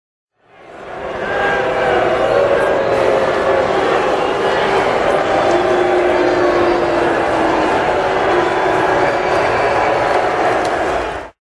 Crowd Booing Sound Effects